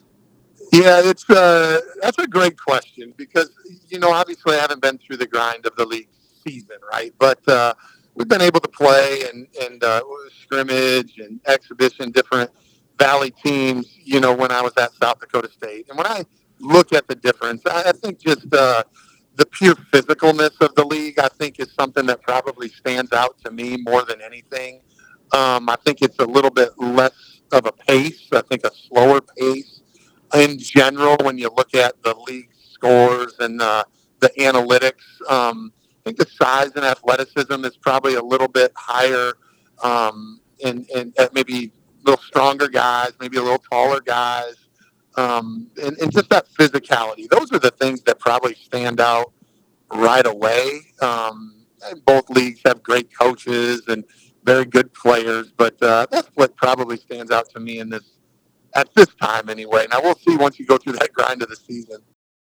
During our interview he repeatedly talks about other people.